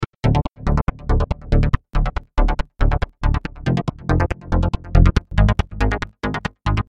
硬核恍惚低音
描述：在korg monopoly上做了这个，并不是真正的低音合成器，但通过操纵截止点和时间以及音调转换器将其变成了低音。
Tag: 120 bpm Trance Loops Bass Synth Loops 1.35 MB wav Key : Unknown